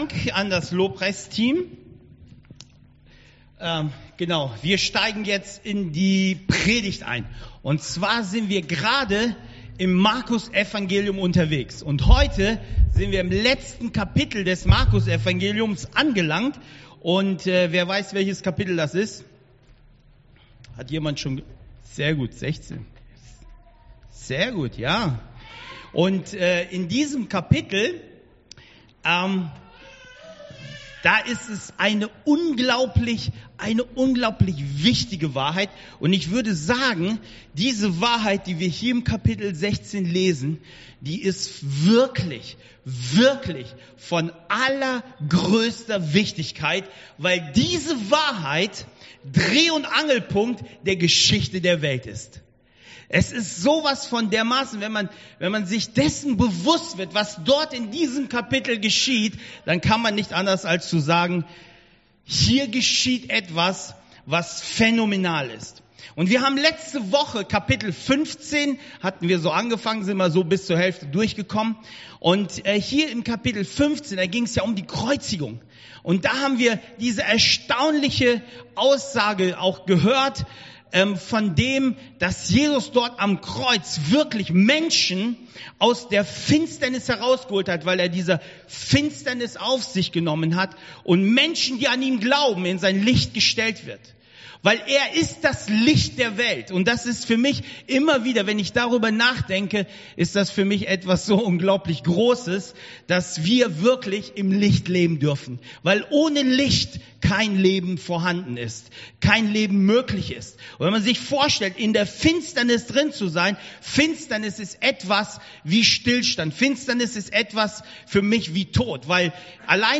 Predigt 01.08.2021